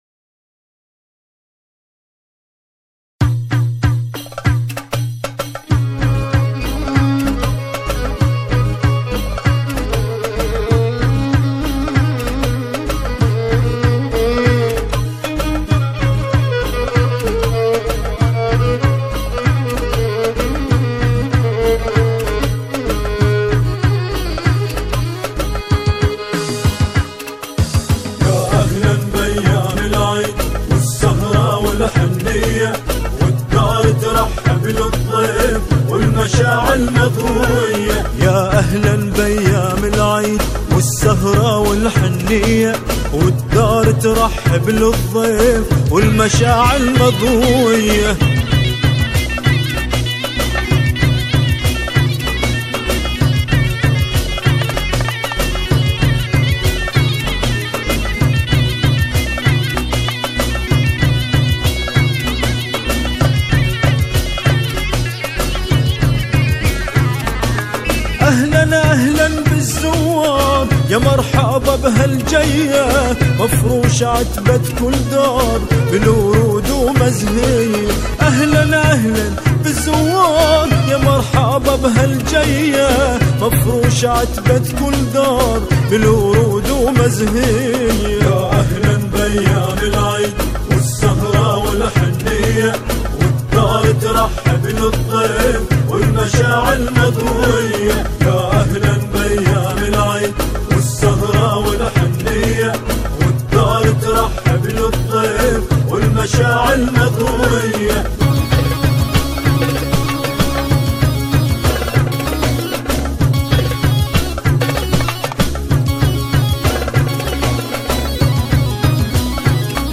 گروهی از همخوانان هستند